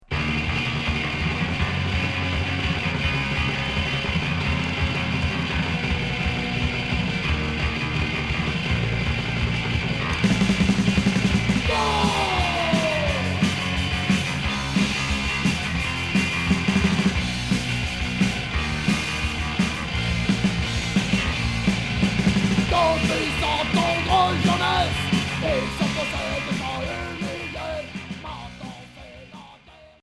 Oi